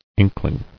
[in·kling]